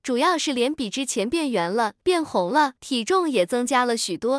tts_result_1.wav